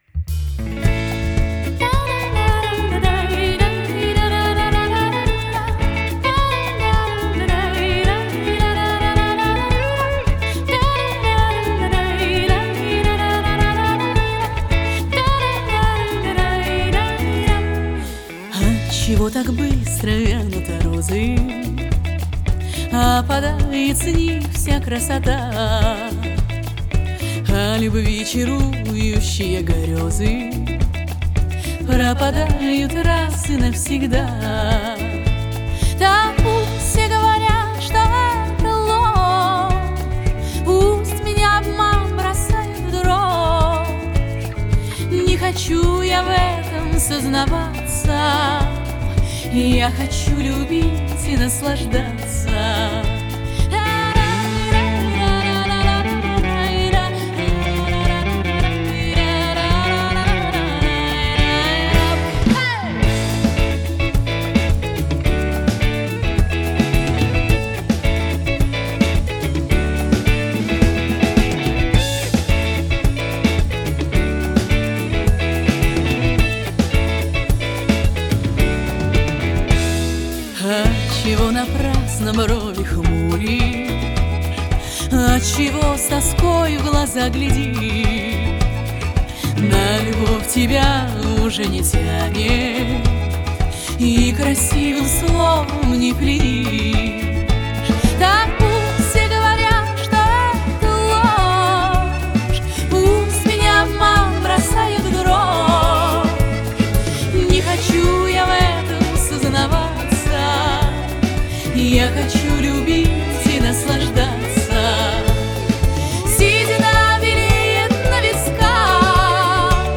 Казачий романс